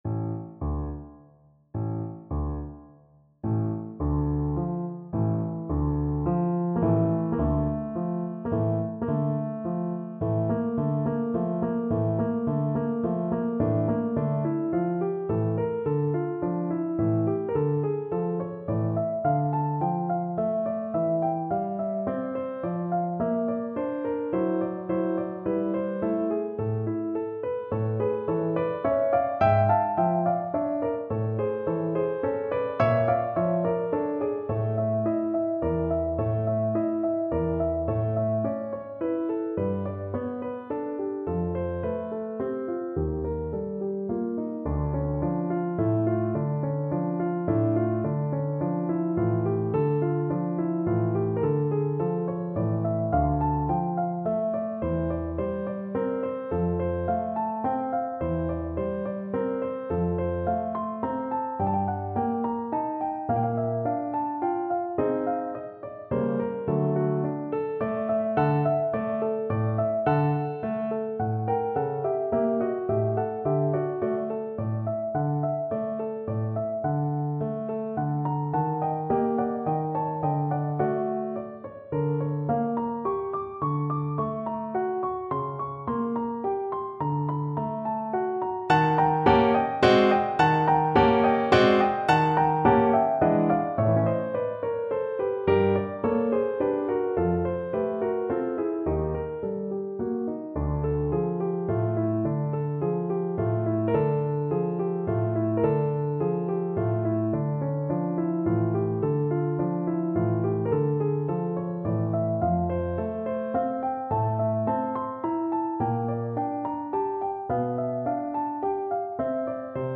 Classical
Trombone version